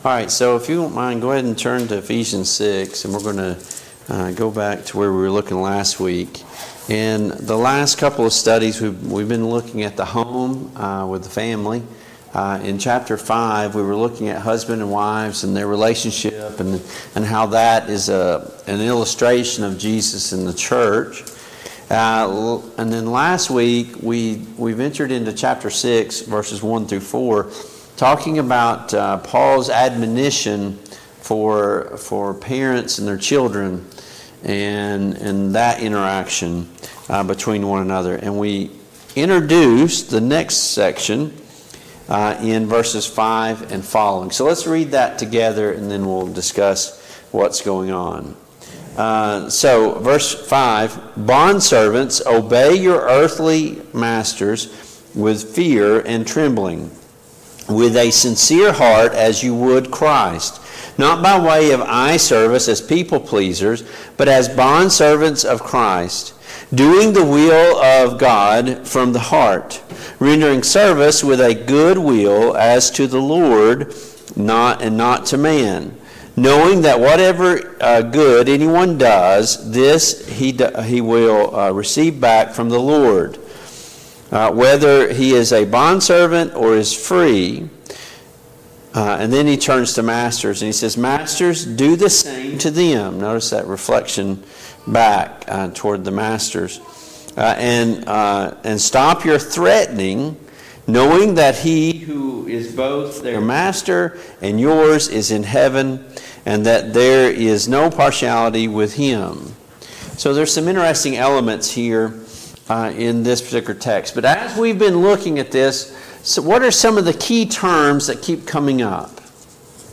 Passage: Ephesians 6:5-20 Service Type: Mid-Week Bible Study